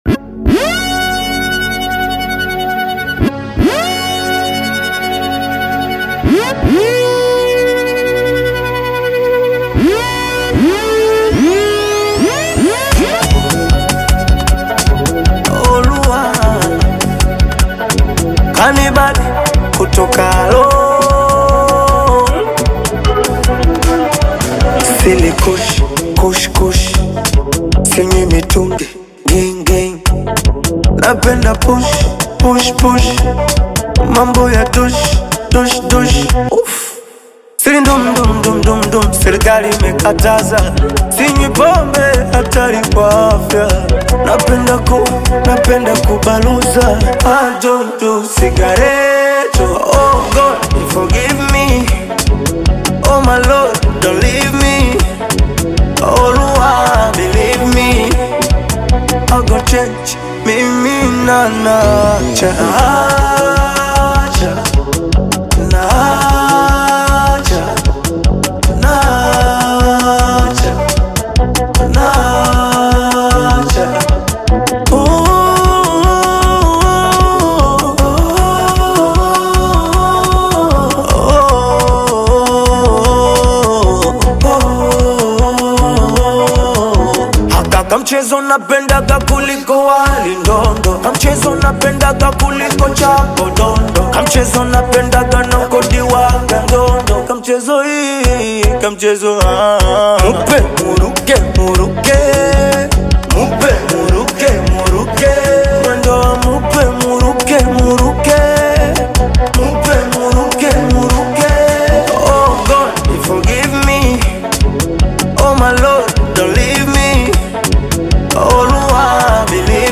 heartfelt and uplifting song